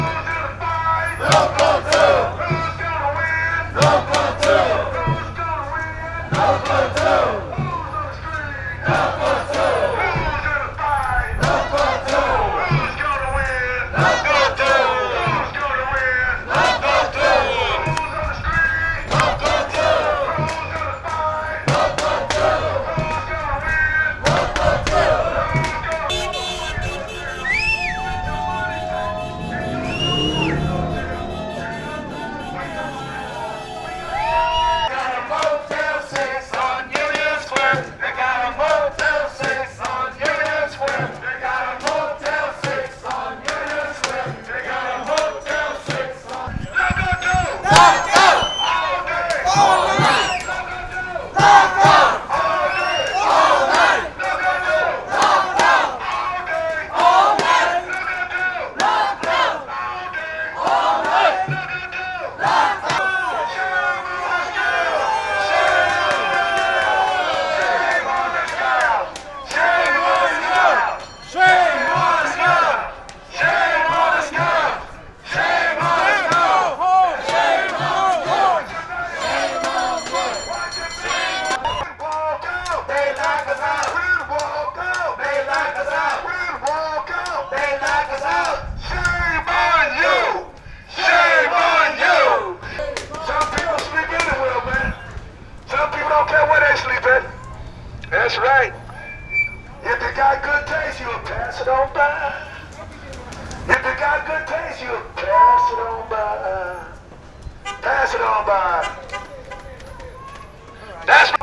Sounds and Interviews from the Westin St. Francis picket line after the UNITE/HERE Local 2 rally in Union Square
Chants & other stuff
sounds-o-picket_-st.francis.wav